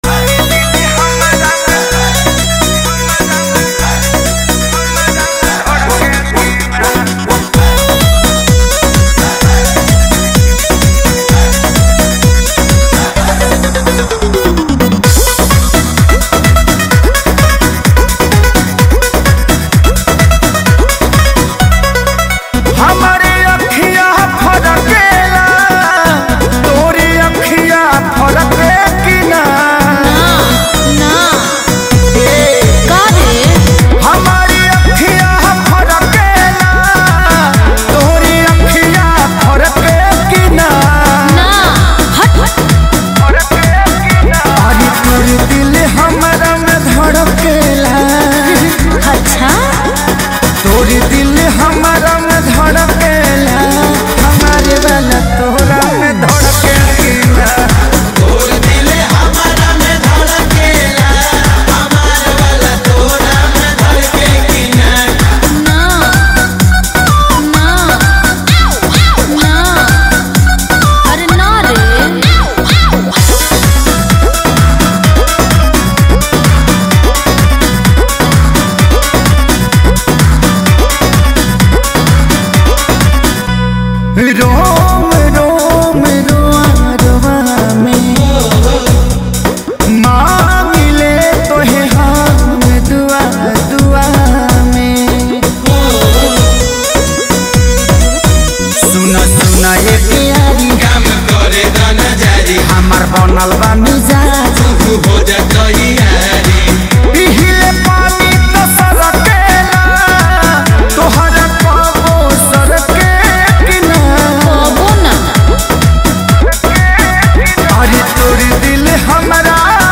Category Bhojpuri New Mp3 Songs Singer(s